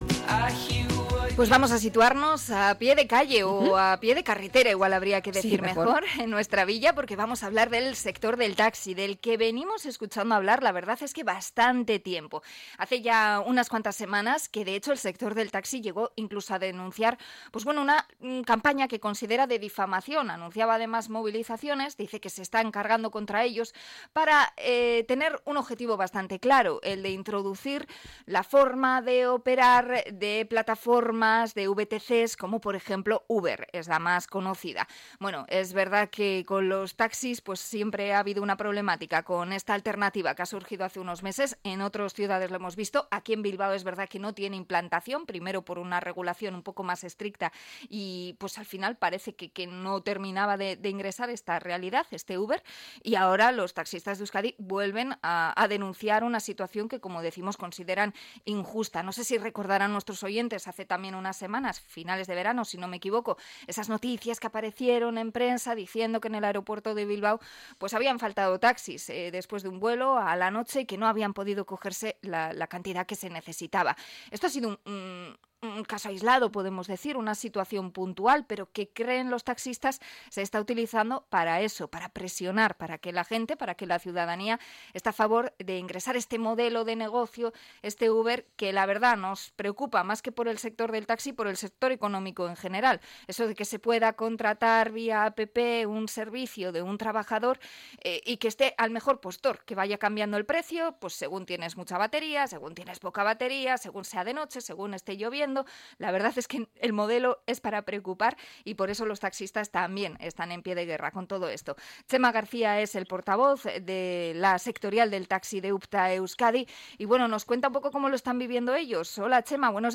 Entrevista a los taxistas de UPTA Euskadi